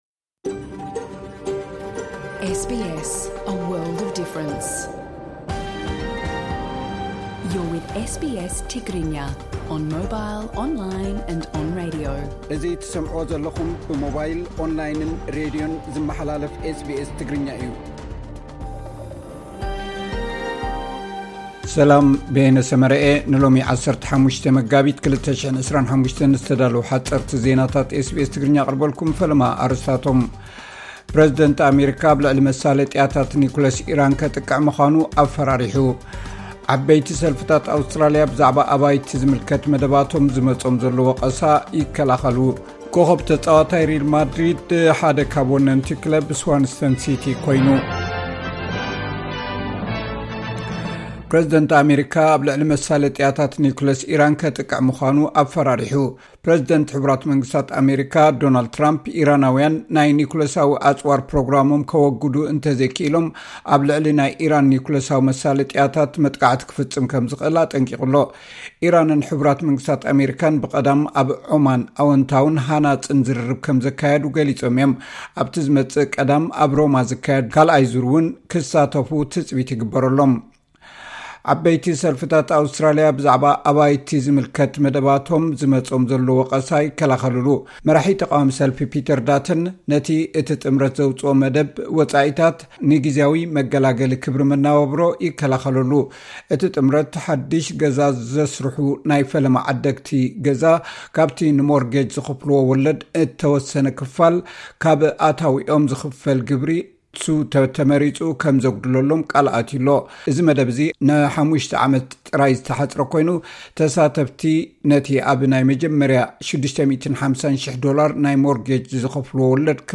ሓጸርቲ ዜናታት ኤስ ቢ ኤስ ትግርኛ (15 መጋቢት 2025)